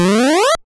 その他の効果音
溜めるＡ５段